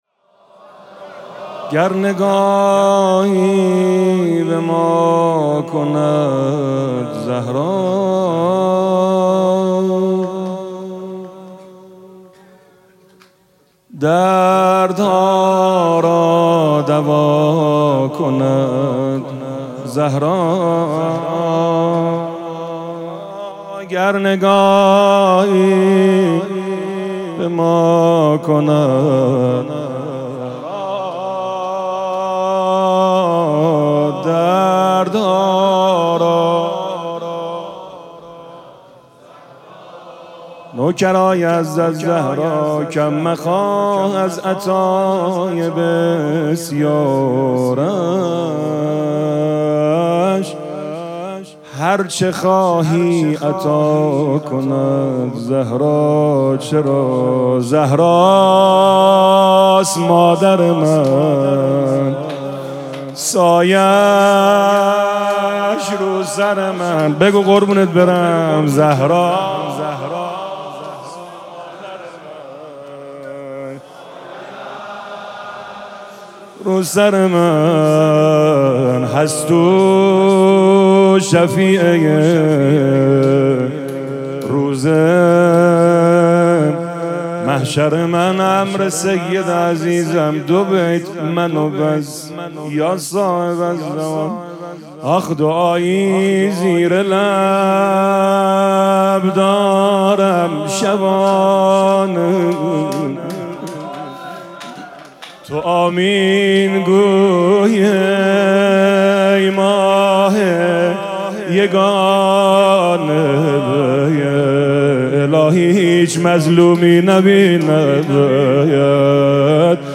شب سوم مراسم عزاداری دهه دوم فاطمیه ۱۴۴۶
حسینیه ریحانه الحسین سلام الله علیها
روضه